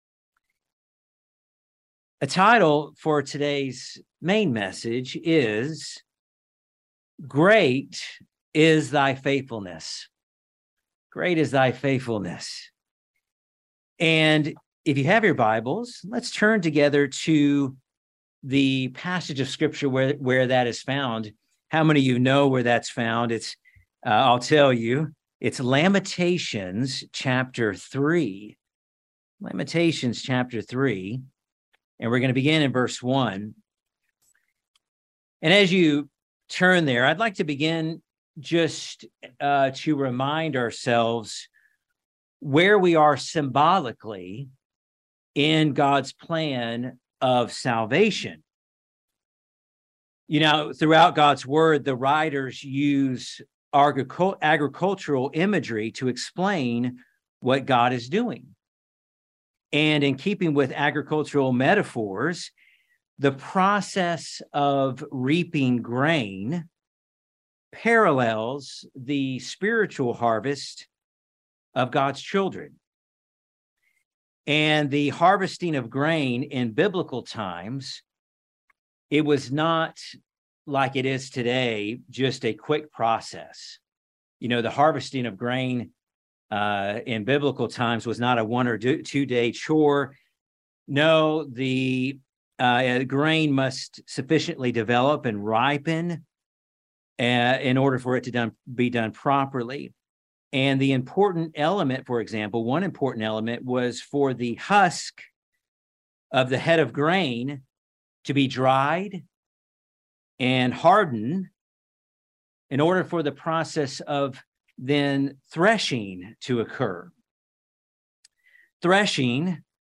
This sermon will reveal through Scripture the fact that God is faithful, and we can absolutely trust in Him!